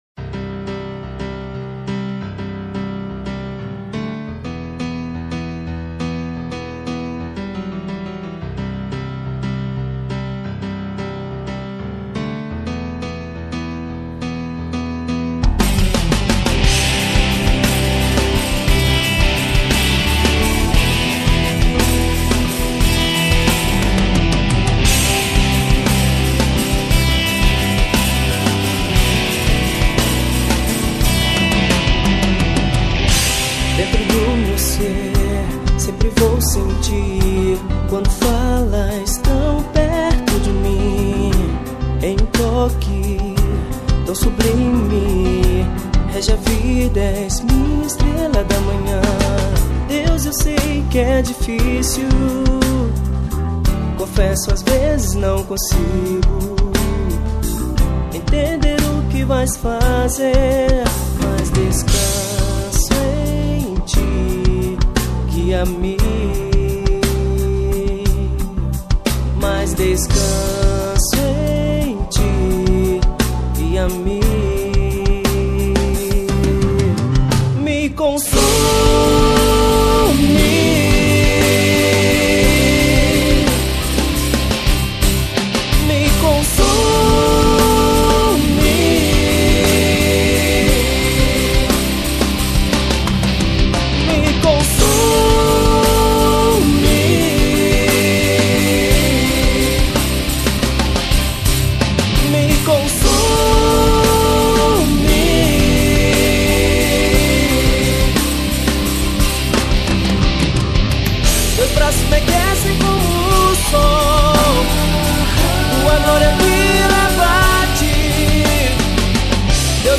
EstiloHard Rock